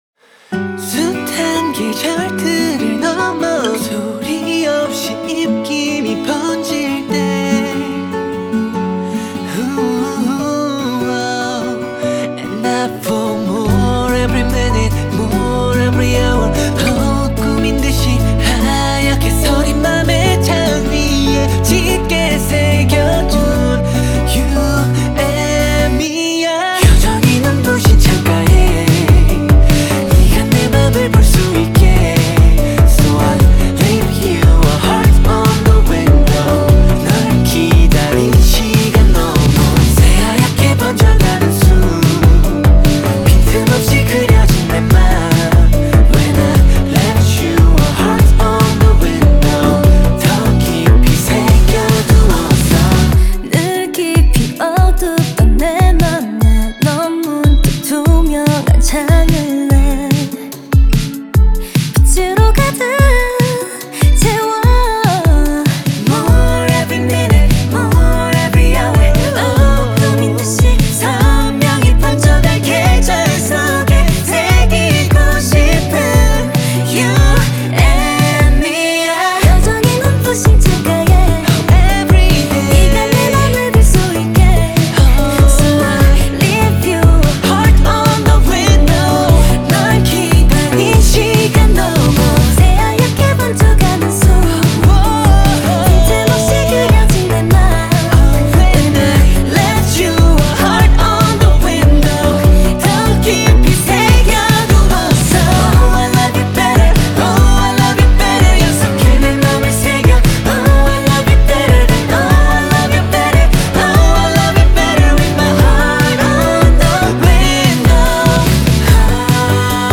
کیپاپ